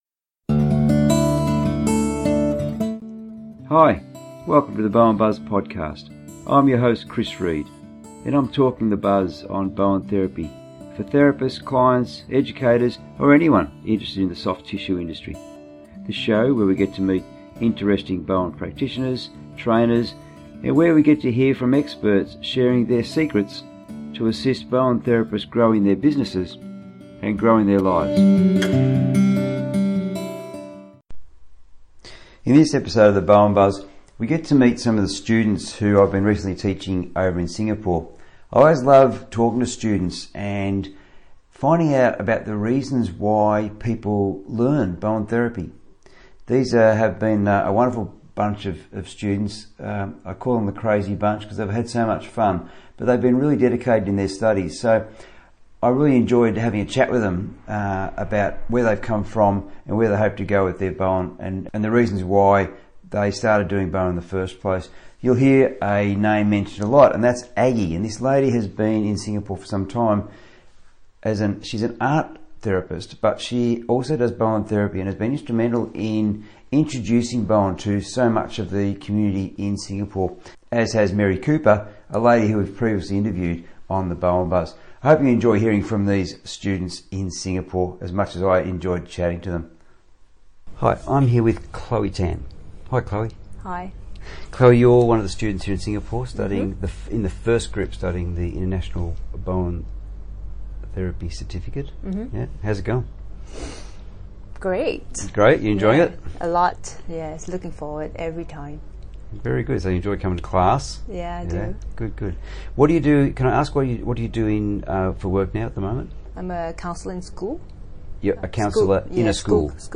In this episode 17 of the BowenBuzz we get to meet the class of the first ever International Certificate of Bowen Therapy.